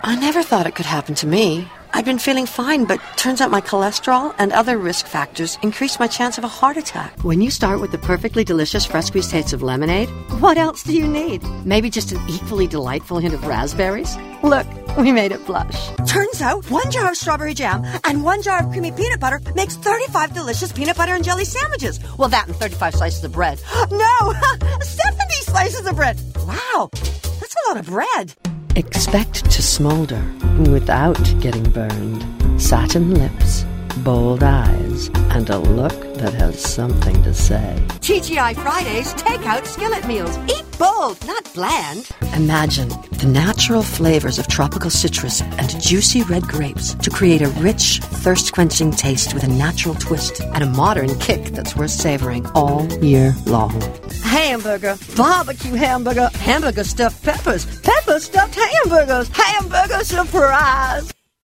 rich, warm, smooth, soothing, calming, sultry, confident, and comforting
Sprechprobe: Werbung (Muttersprache):